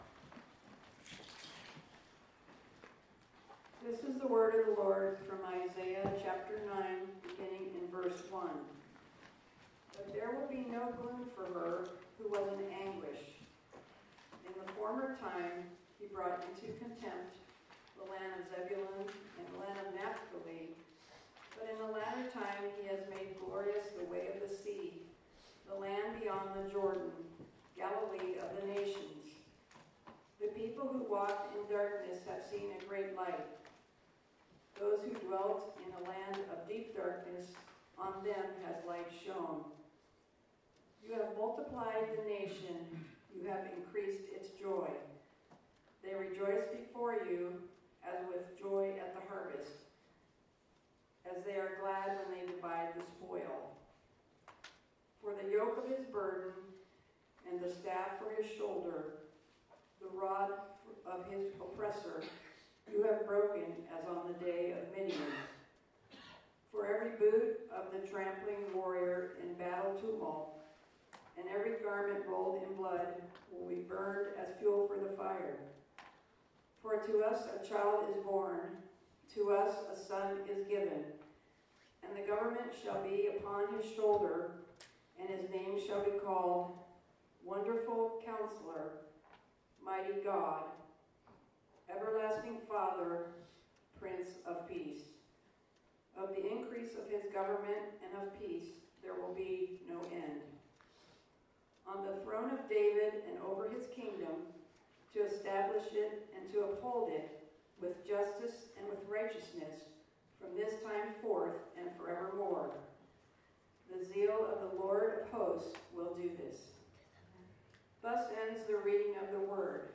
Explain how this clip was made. Service Type: Sunday Morning Topics: God will not forget his people , God's solution is a child , Light will shine in darkness , Names of Jesus